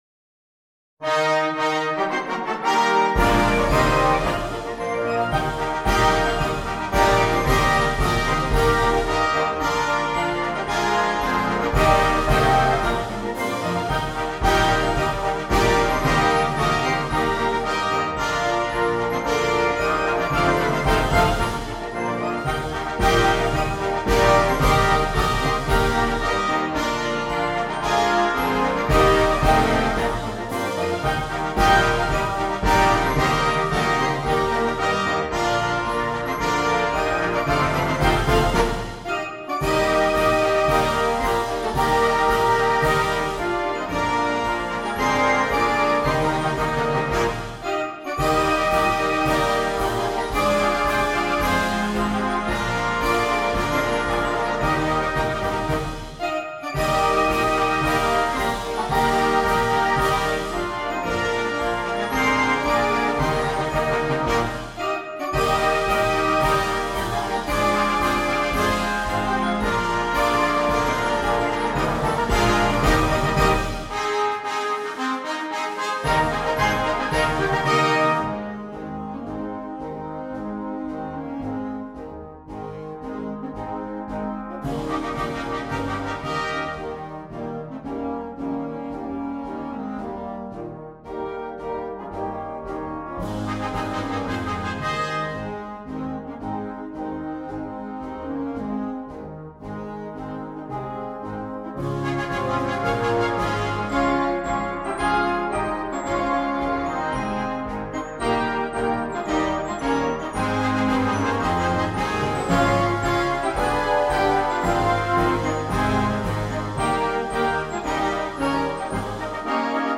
Konzertmarsch - Concert March